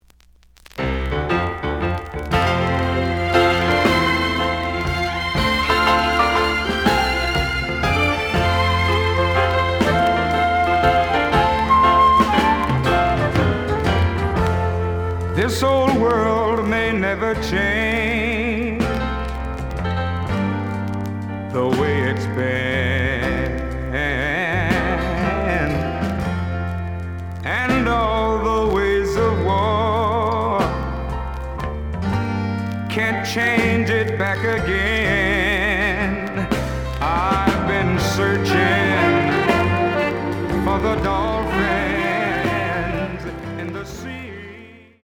The audio sample is recorded from the actual item.
●Genre: Soul, 60's Soul
Slight noise on B side.)